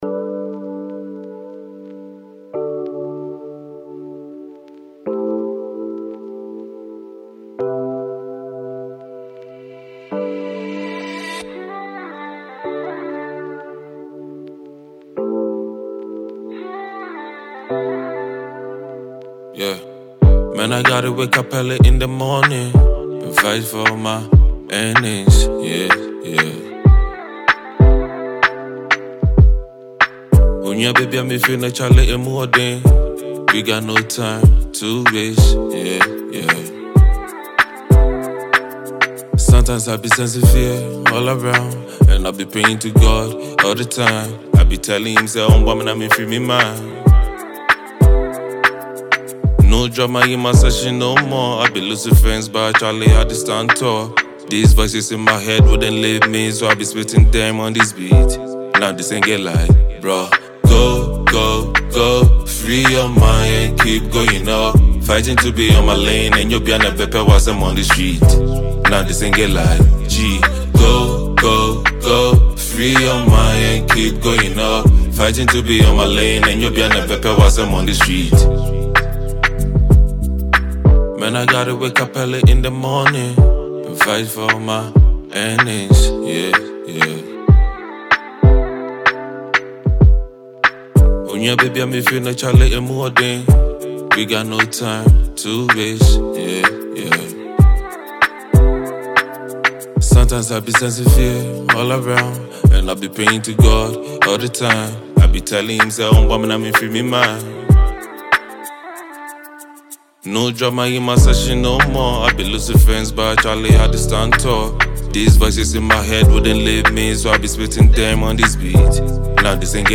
Ghana Music
Ghanaian rapper